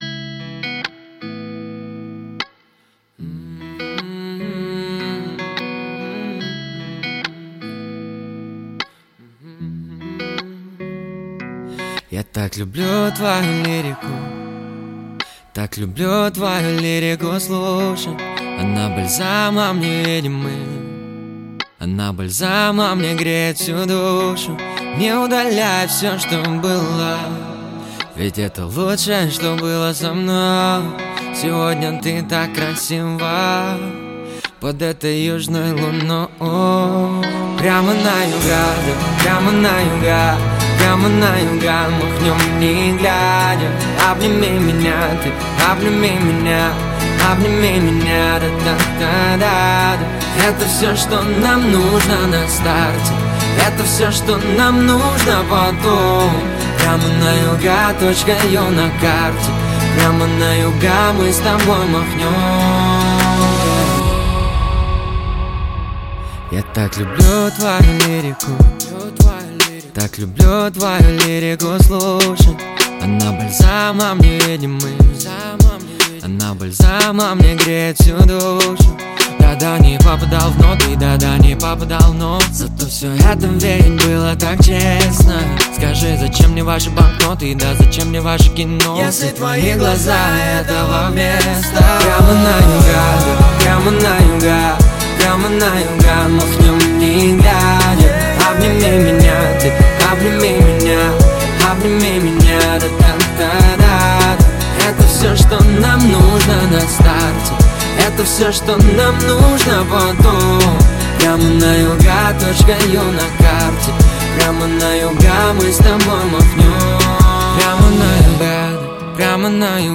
Русский рэп
Жанр: Русский рэп / R & B